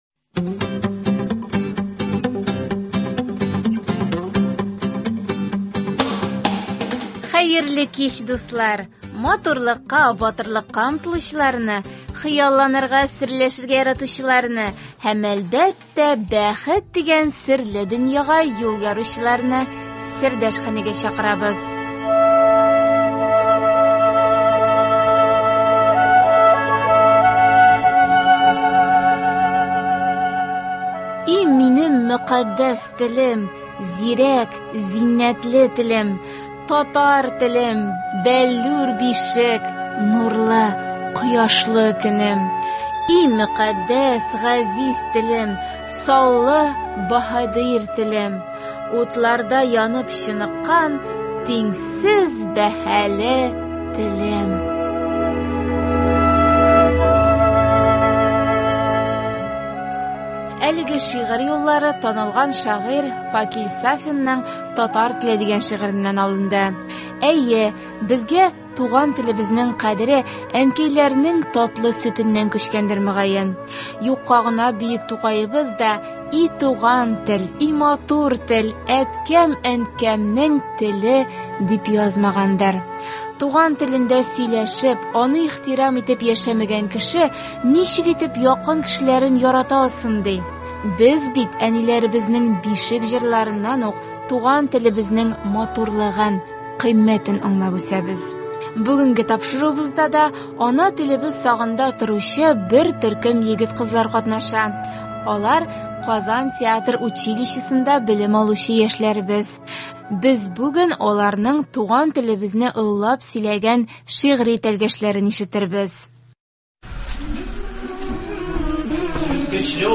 Бүгенге тапшыруыбызда ана телебез сагында торучы бер төркем егет – кызлар катнаша. Без аларның туган телебезне олылап сөйләгән шигьри тәлгәшләрен ишетербез.